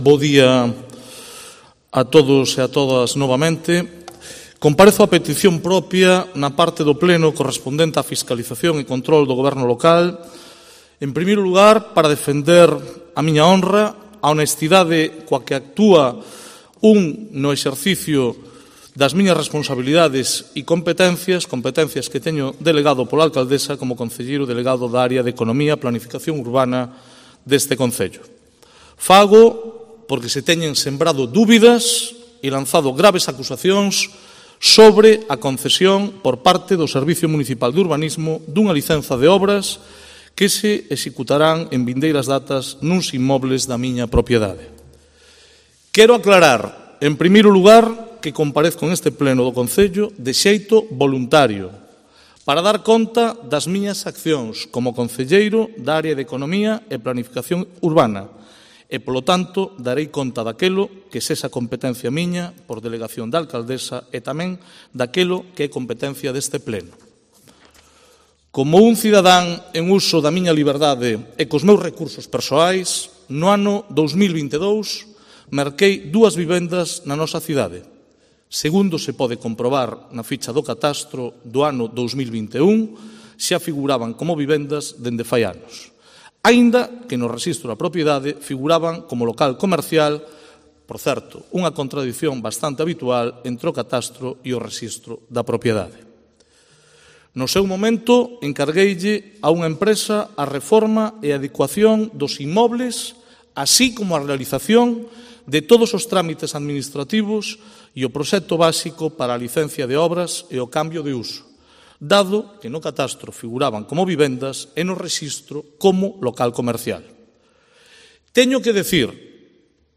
Primer turno de explicaciones de Lage Tuñas en el Pleno Municipal